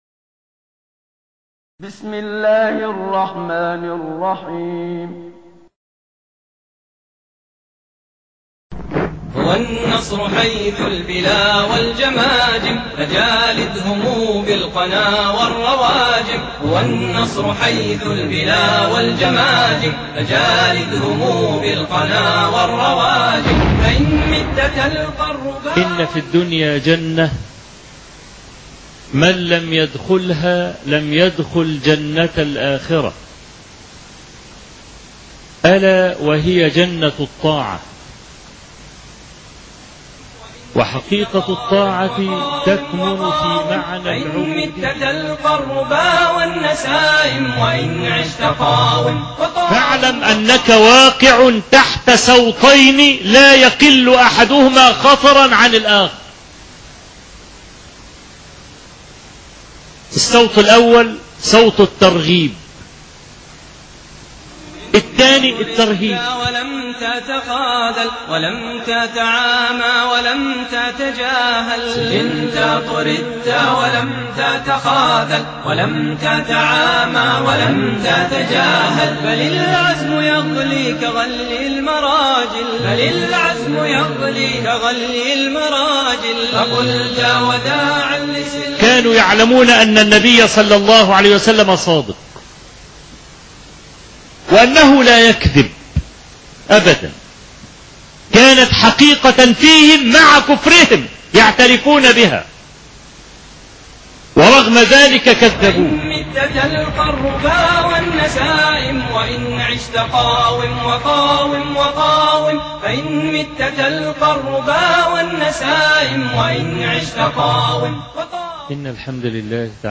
الخطبة الرابعة (سلم الصعود )